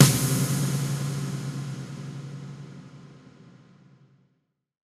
Big Drum Hit 09.wav